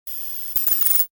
scan1.ogg